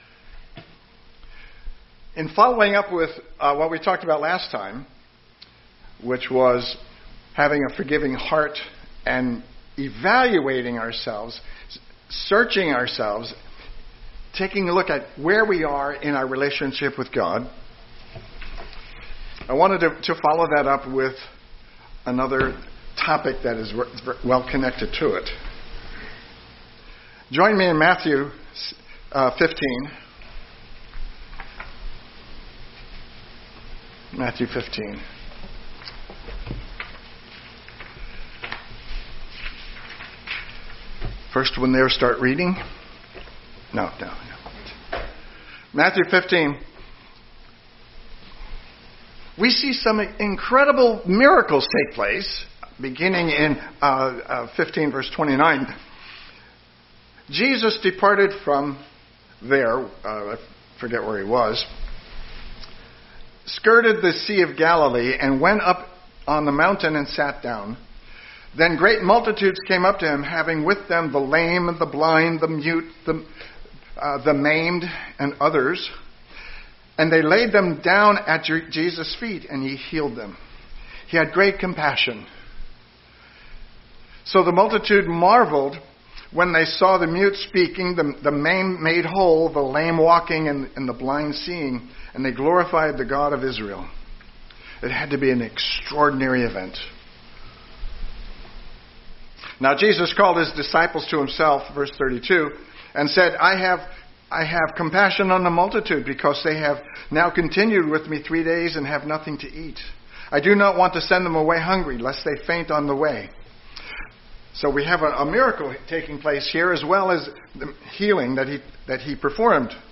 Sermons
Given in Eureka, CA